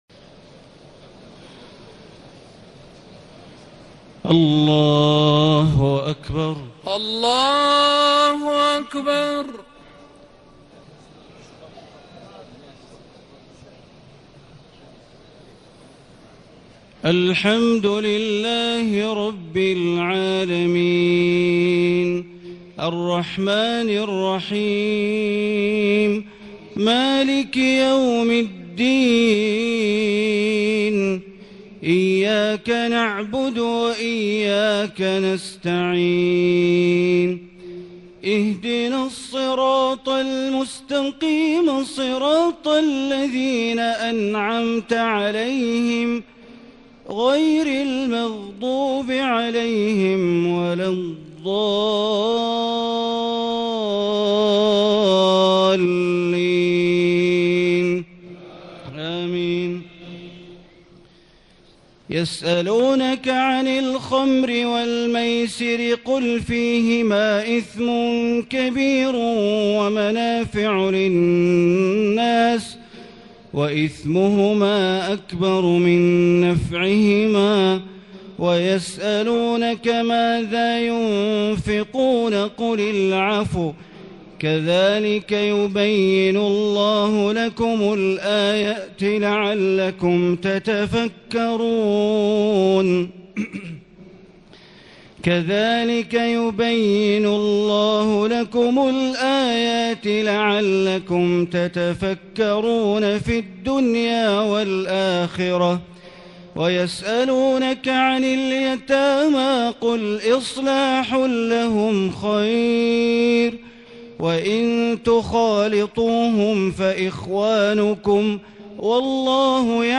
تهجد اليلة الثانية والعشرين من رمضان ١٤٣٩ من سورة البقرة اية ٢١٩ إلى اية ٢٥٢ > تراويح ١٤٣٩ هـ > التراويح - تلاوات بندر بليلة